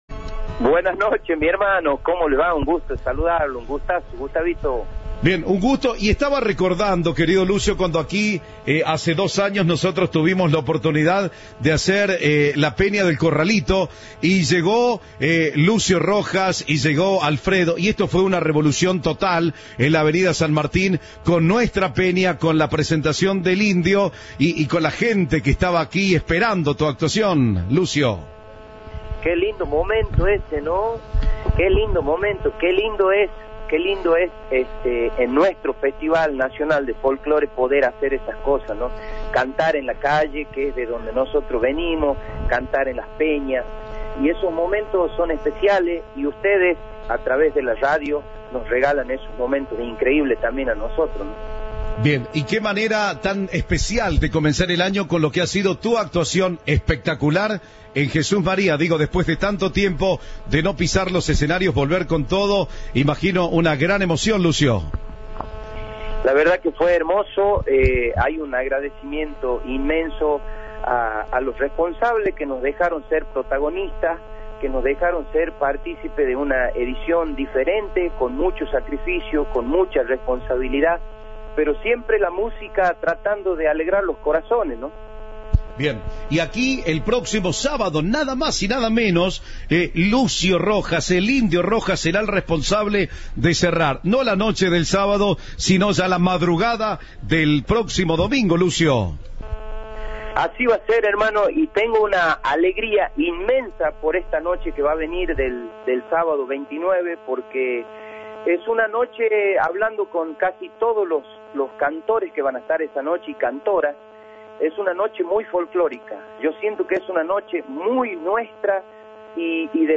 El músico se presentará el próximo 29 de enero en la 62ª edición del Festival Nacional de Folklore.
Entrevista